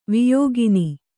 ♪ viyōgini